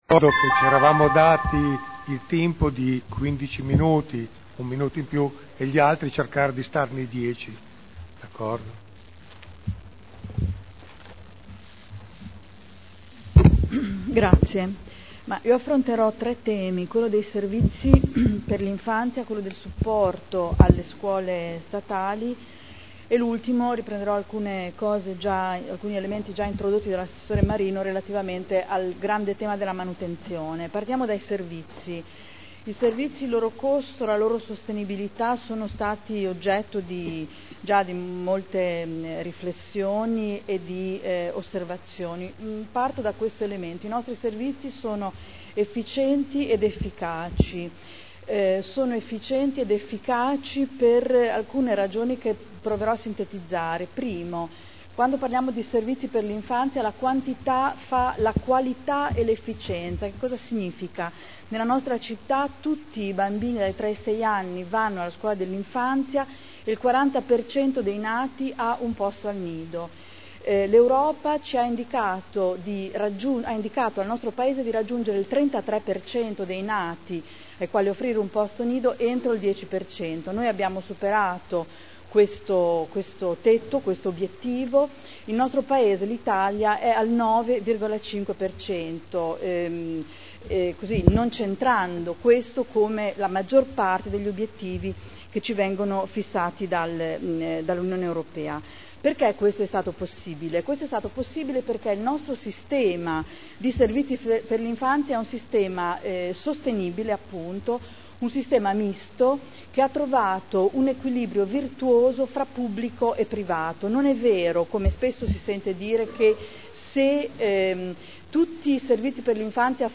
Adriana Querzè — Sito Audio Consiglio Comunale
Dibattito sul bilancio